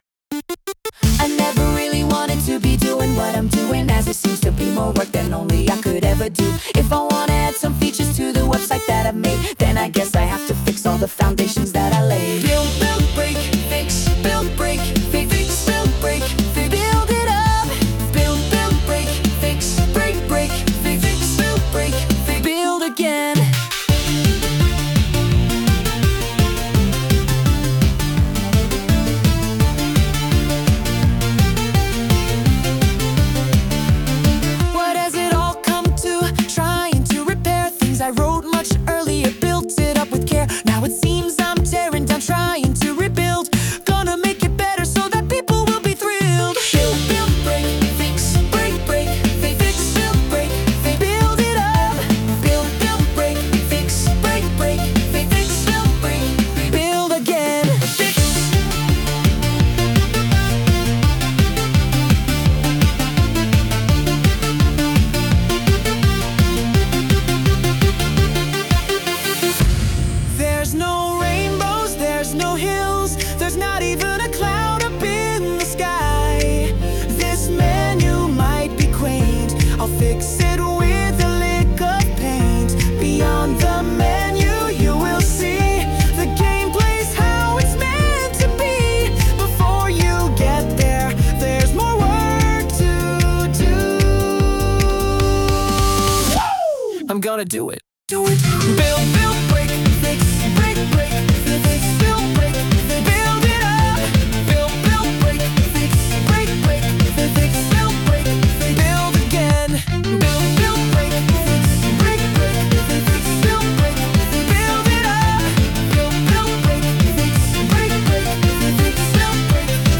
Lyrics : By me
Sung by Suno